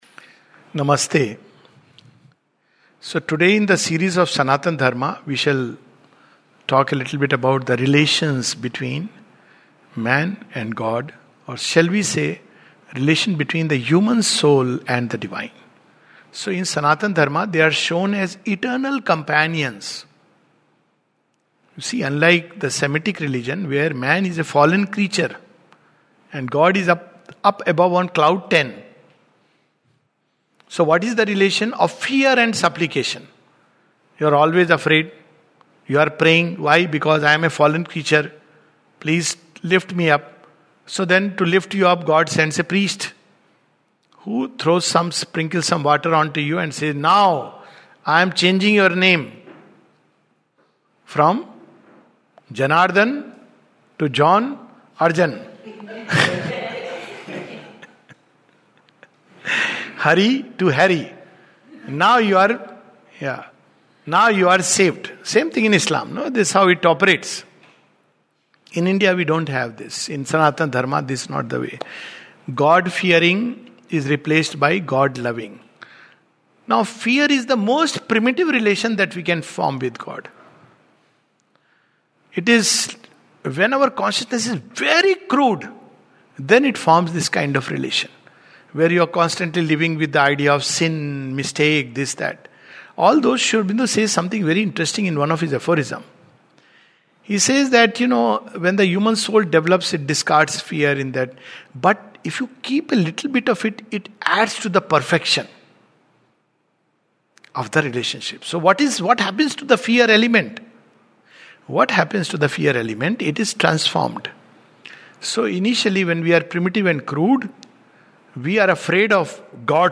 This talk focuses on the various relations that man can have with God in the light of Sanatana Dharma.